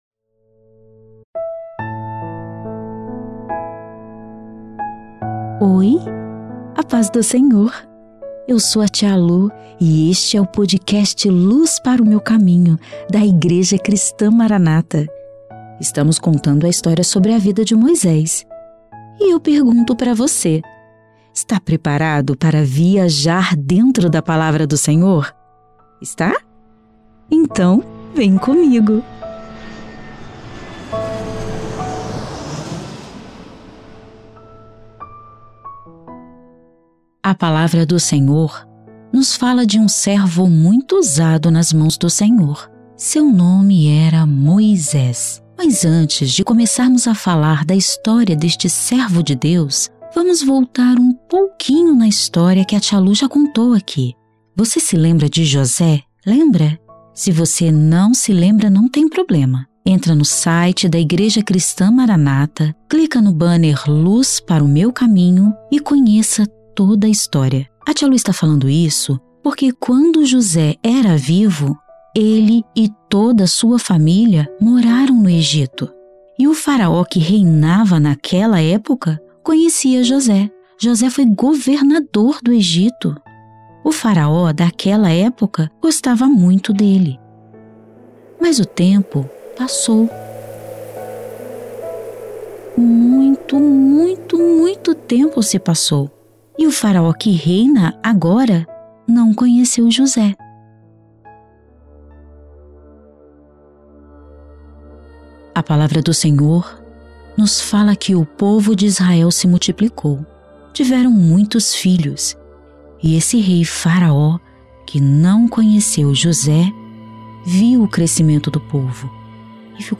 O quadro Luz Para o Meu Caminho traz histórias da bíblia narradas em uma linguagem para o público infantil.